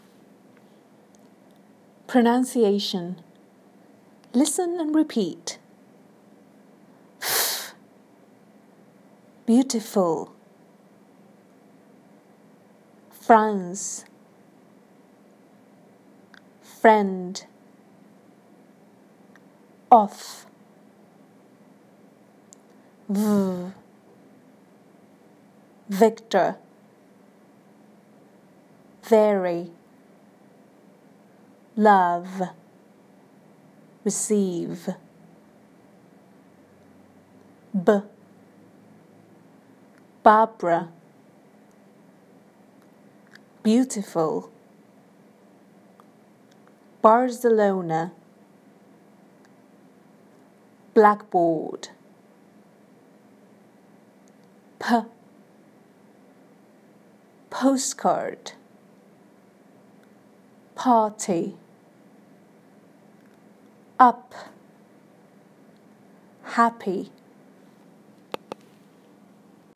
Listen and repeat the following sounds and words.